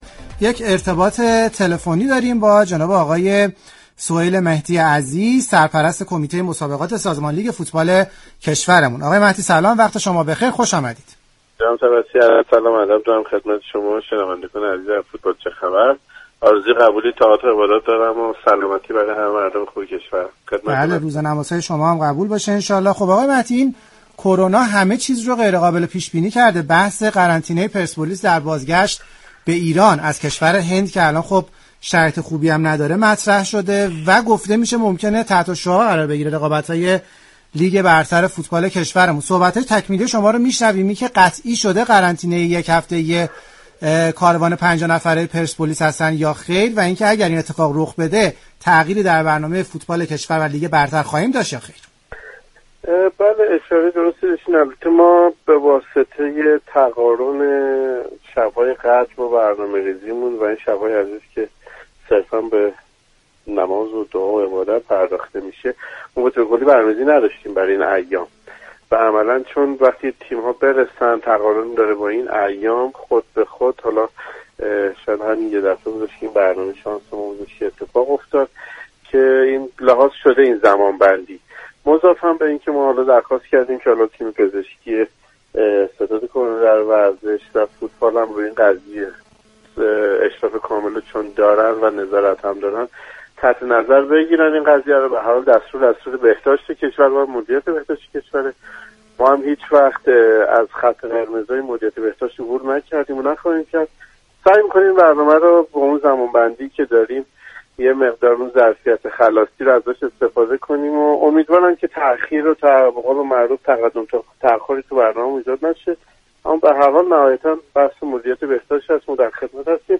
شما می توانید از طریق فایل صوتی پیوست شنونده ادامه این گفتگو باشید.